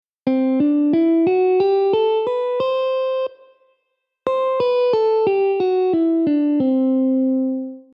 Lydian Scale: Examples
(C-D-E-F#-G-A-B)
Lydian-AUDIO.mp3